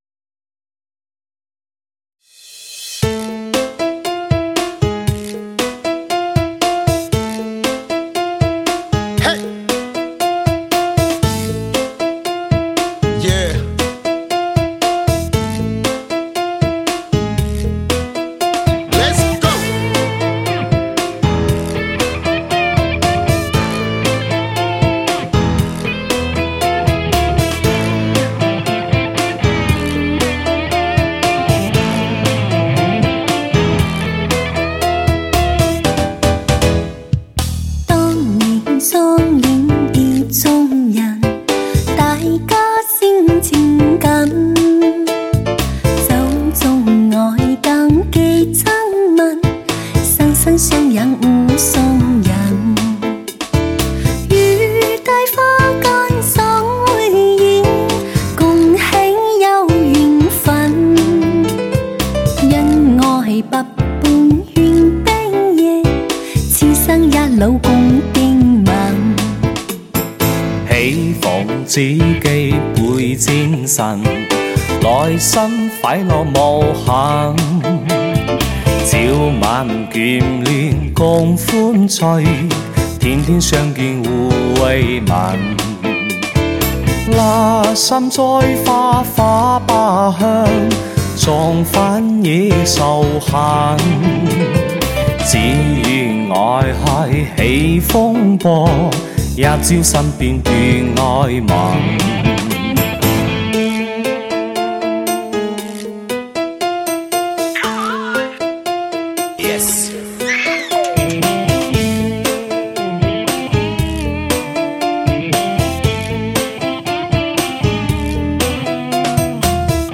最新对唱专辑